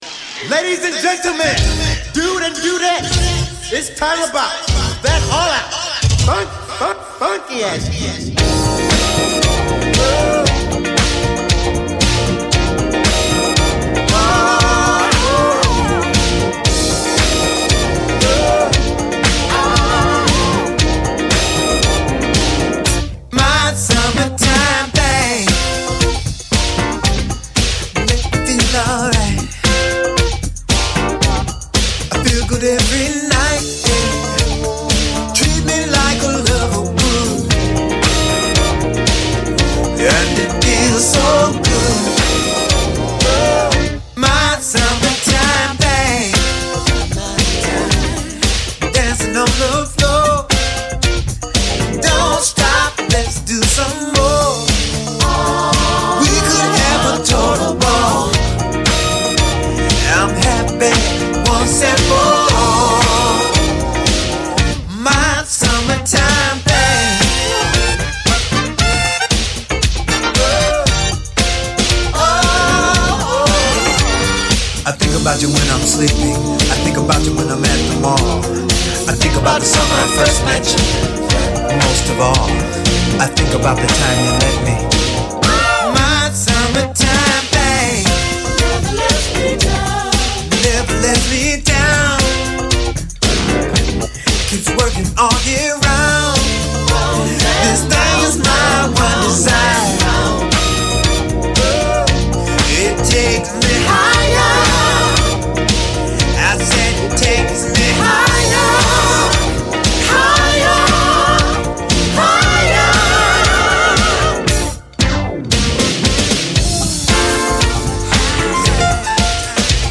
Recorded at Sunset Sound - Hollywood, CA.